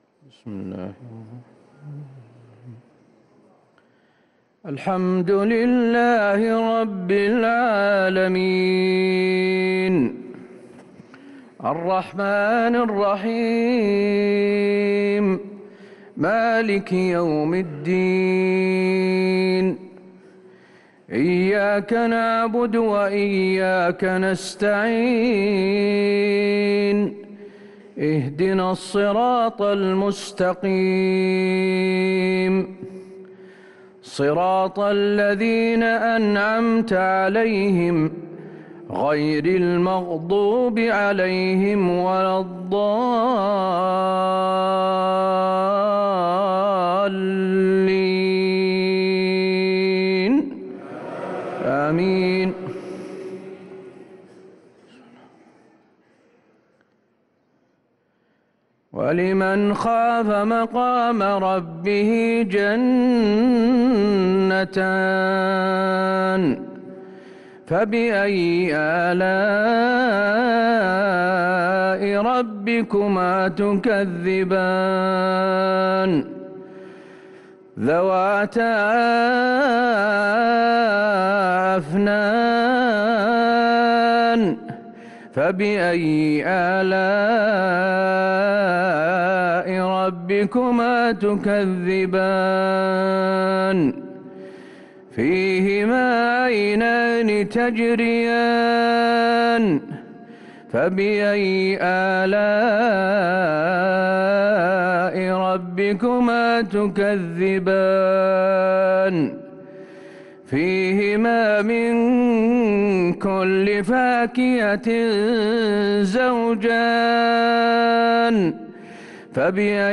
صلاة العشاء للقارئ حسين آل الشيخ 24 رمضان 1444 هـ